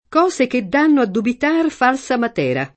materia [ mat $ r L a ] s. f.